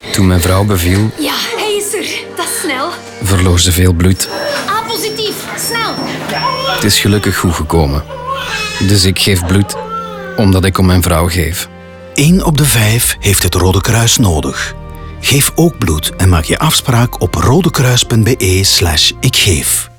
RADIO_RKV_GEEF BLOED